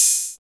808TEK OHH.wav